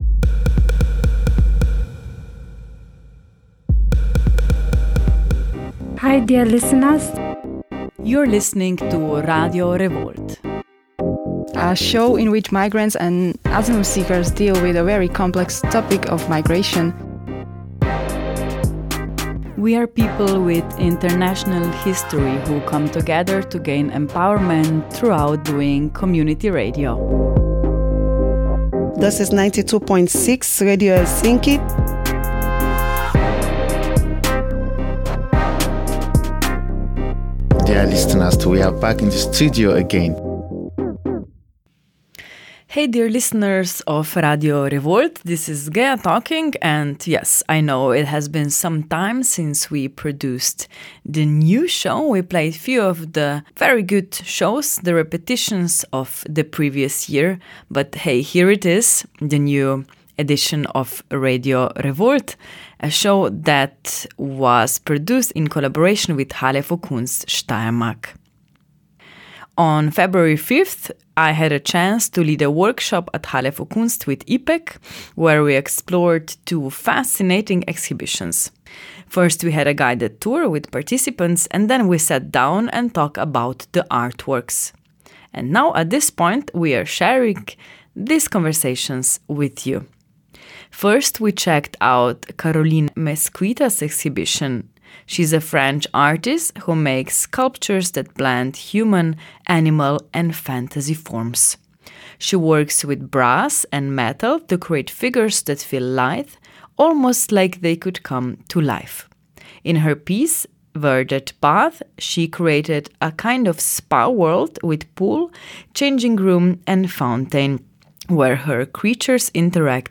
Listen in for a look at the exhibitions and some interesting discussions from the workshop!